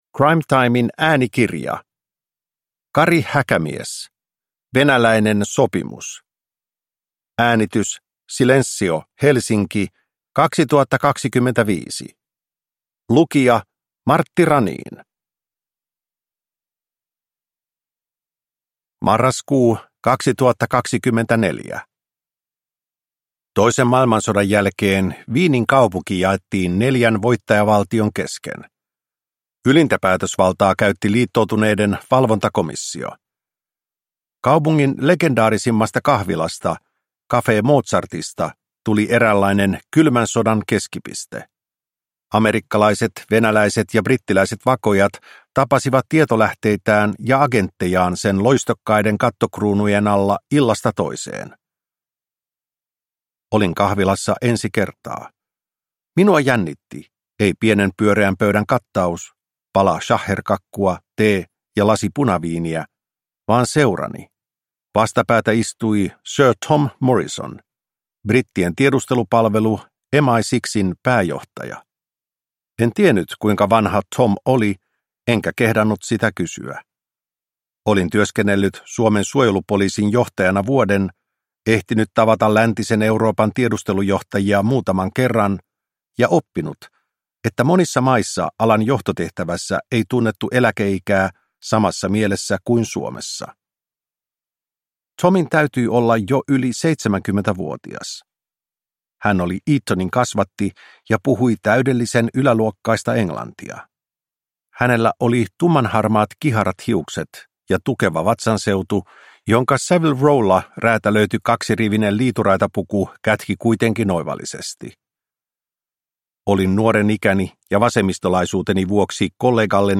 Venäläinen sopimus (ljudbok) av Kari Häkämies